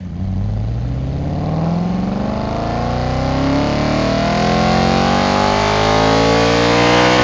v8_13_accel.wav